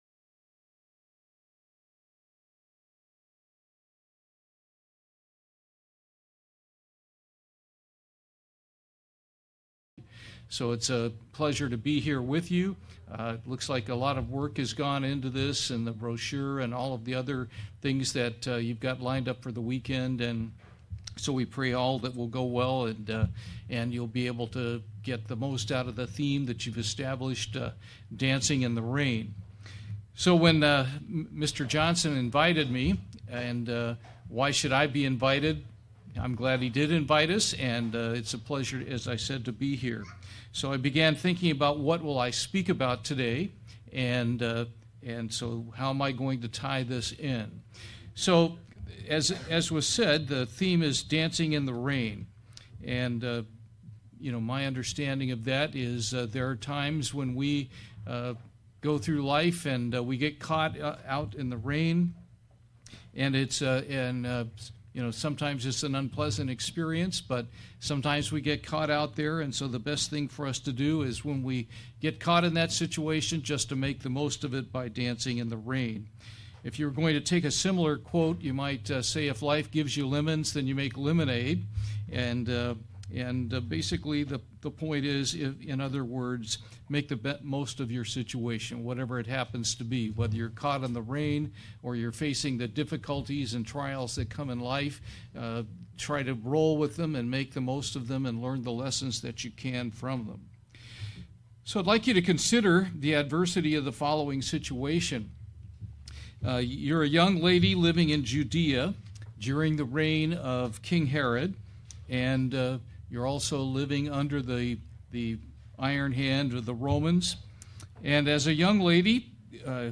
Given in Springfield, MO